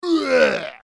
呕吐音效.MP3